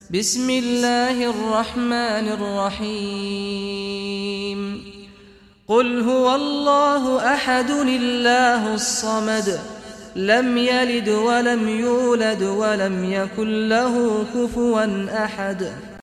Surah Al-Ikhlas Recitation by Sheikh Saad Ghamdi
Surah Al-Ikhlas, listen or play online mp3 tilawat / recitation in Arabic in the beautiful voice of Sheikh Saad al Ghamdi.